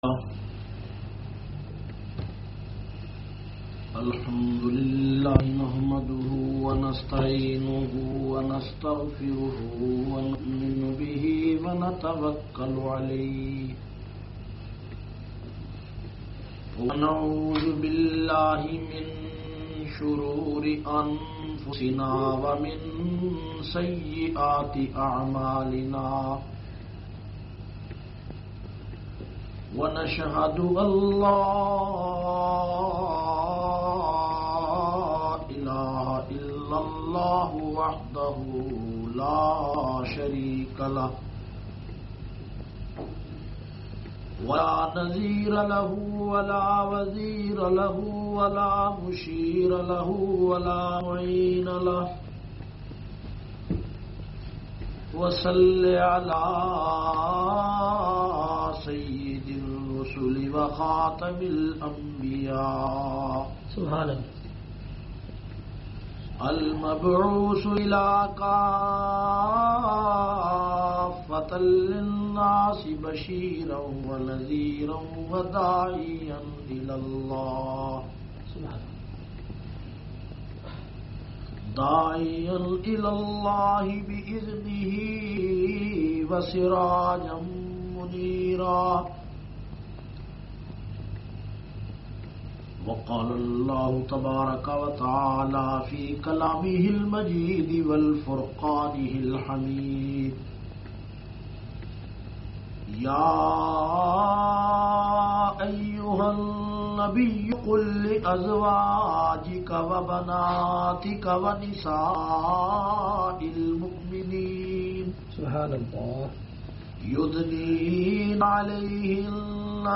360- Seerat un Nabi Conference Idara Taleem ul Quran Edinburgh England (khawateen se khittab).mp3